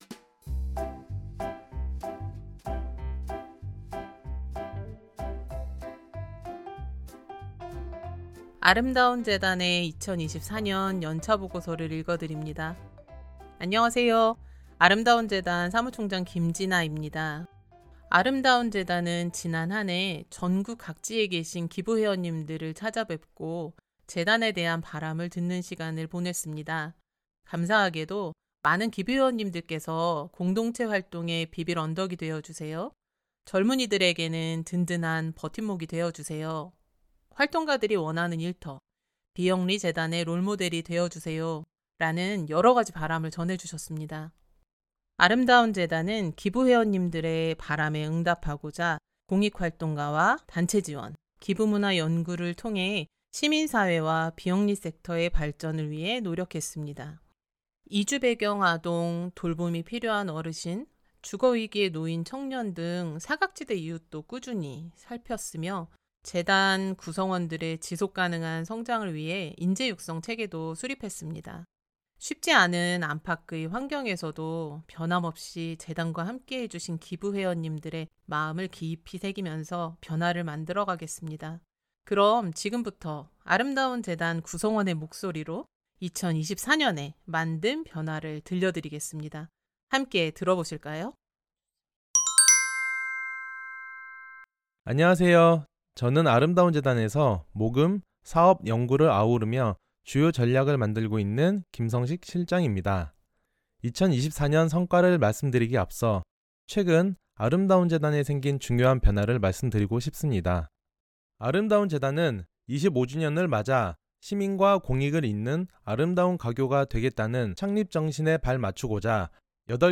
오다시티로 편집한 아름다운재단 2024 오디오 연차보고서는 아래 재생버튼을 클릭하면 들어볼 수 있다.